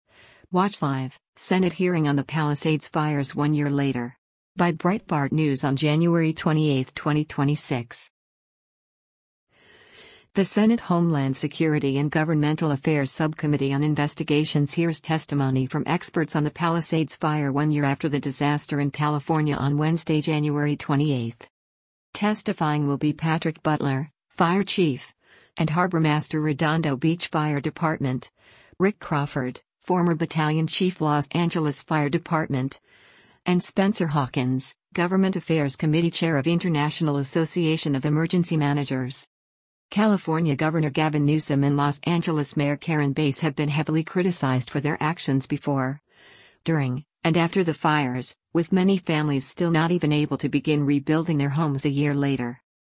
Watch Live: Senate Hearing on the Palisades Fires One Year Later
The Senate Homeland Security and Governmental Affairs Subcommittee on Investigations hears testimony from experts on the Palisades fire one year after the disaster in California on Wednesday, January 28.